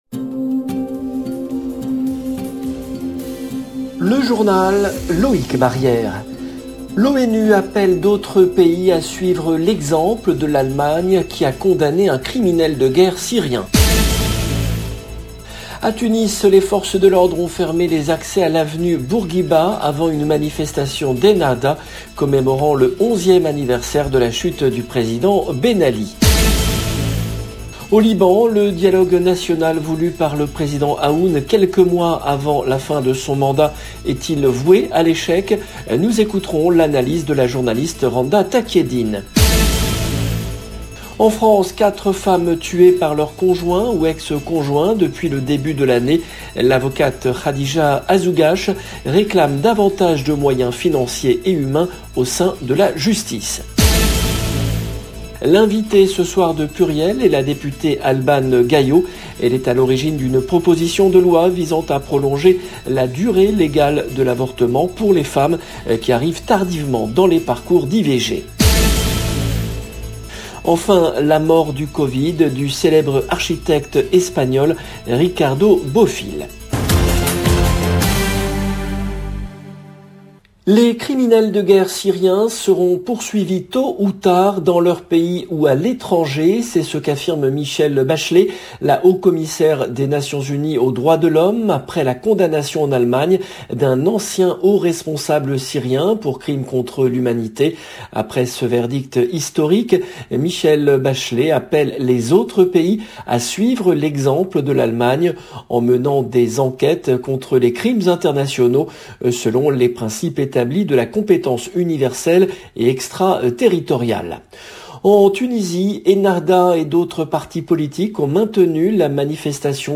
LB JOURNAL EN LANGUE FRANÇAISE
L’invitée ce soir de PLURIEL est la députée Albane Gaillot.